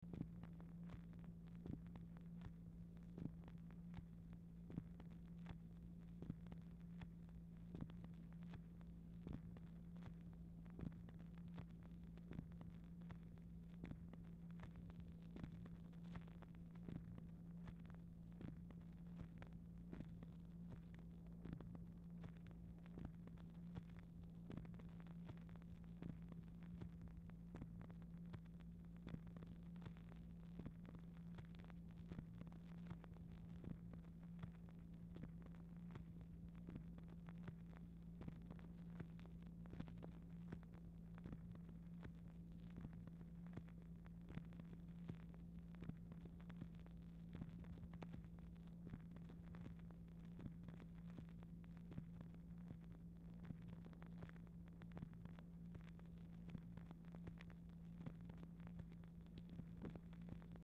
Telephone conversation # 3689, sound recording, MACHINE NOISE, 6/11/1964, time unknown | Discover LBJ
Format Dictation belt
Series White House Telephone Recordings and Transcripts Speaker 2 MACHINE NOISE